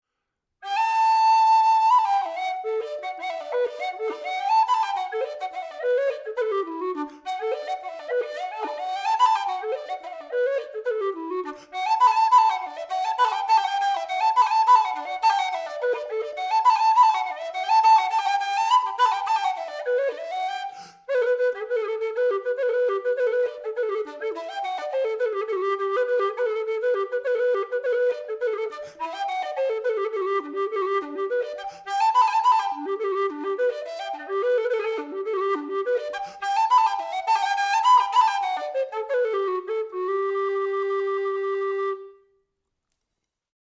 Wooden Low Whistle in D
The sound of the rosewood low whistle takes a position between blackwood and mopane.
The sound samples underneath the pictures demonstrate the wonderful but slightly different sound of these instruments.
Honduras Rosewood, Reels